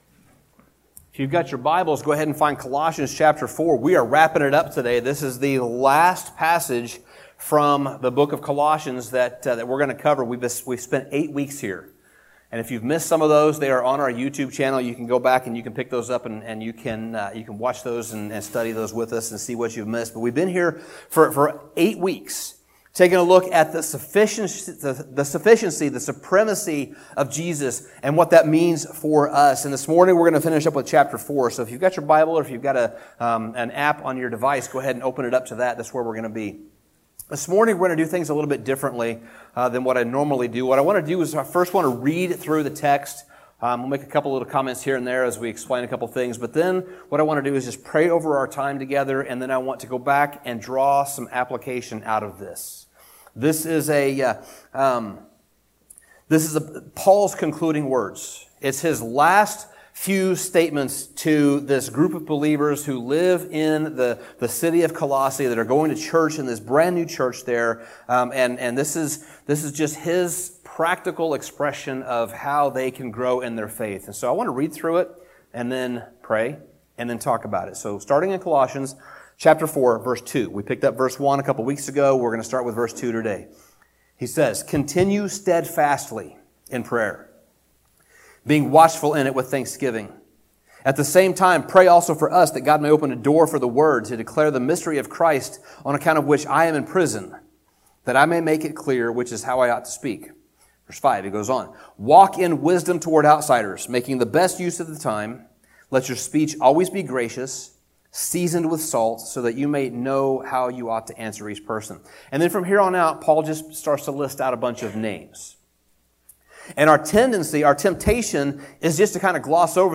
Sermon Summary Colossians is only four chapters long, and just like the rest of Paul's letters, the latter part of it is filled with practical ways to live out our faith in everyday life.